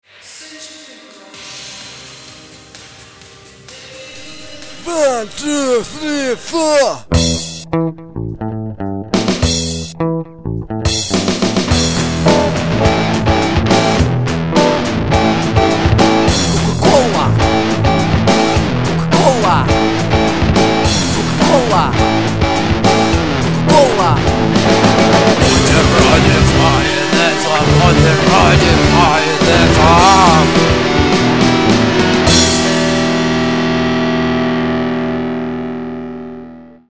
панк-рок группы